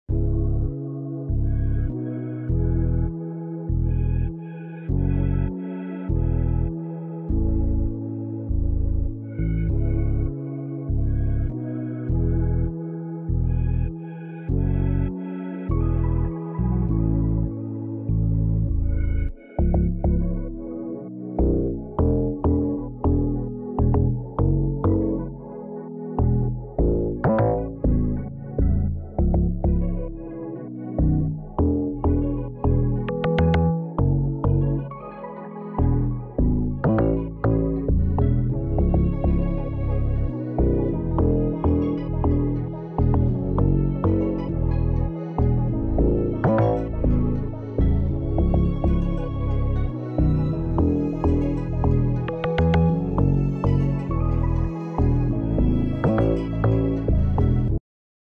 Free Afrobeat Melody Loop Sound Effects Free Download